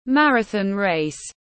Marathon race /ˈmærəθɑːn reɪs/